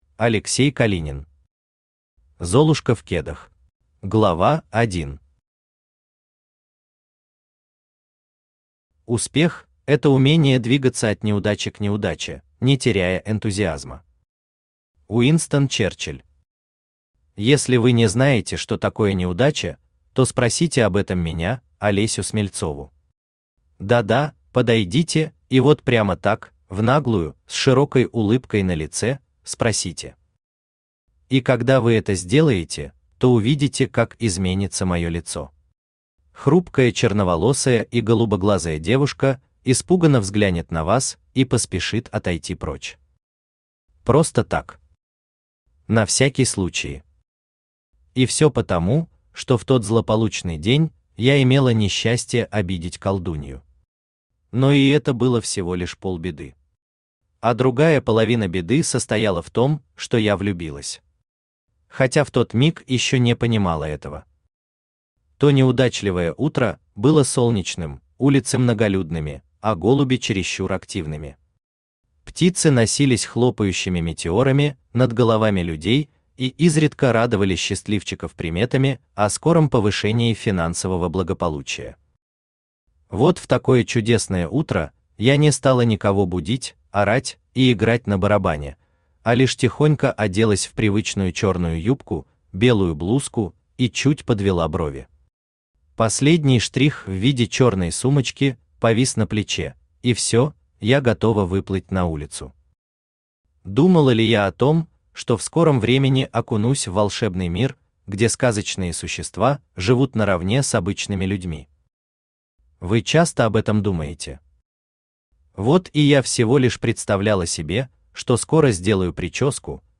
Аудиокнига Золушка в кедах | Библиотека аудиокниг
Aудиокнига Золушка в кедах Автор Алексей Калинин Читает аудиокнигу Авточтец ЛитРес.